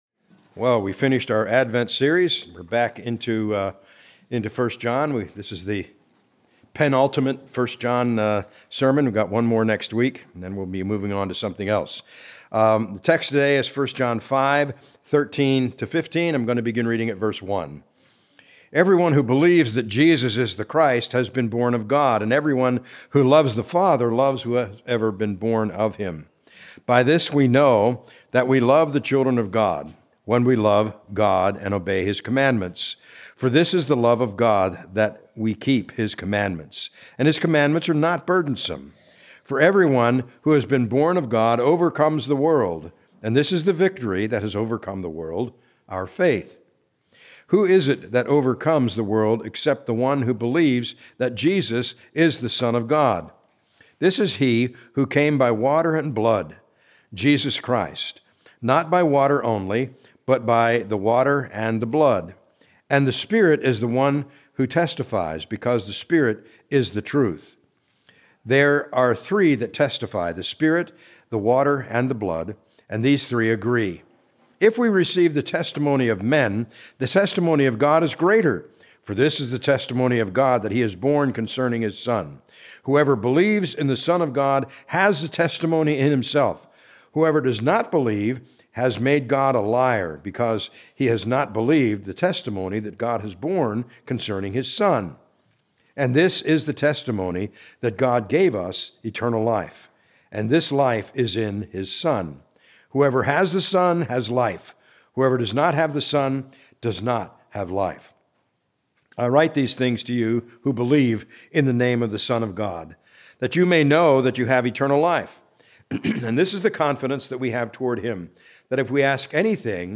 A message from the series "Advent 2024."